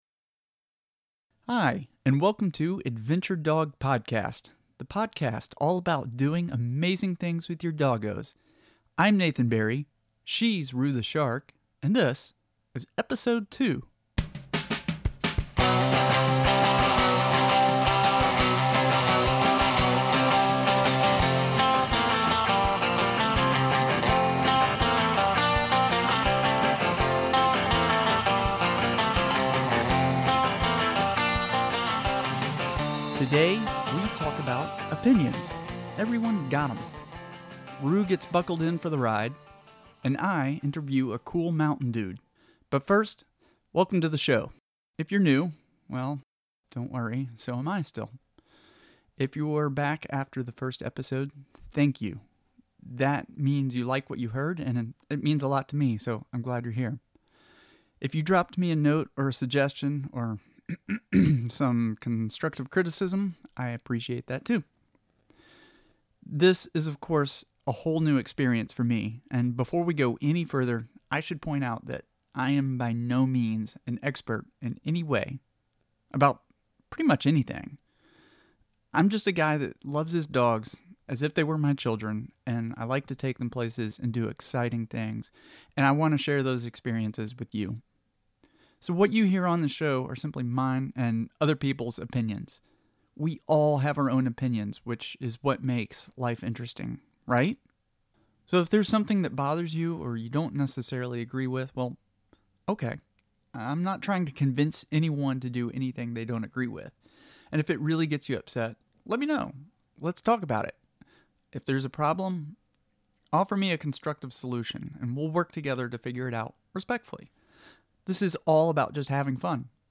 Today's interview